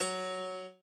admin-amethyst-moon/b_piano1_v100l8-3o5fp.ogg